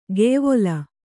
♪ geyvola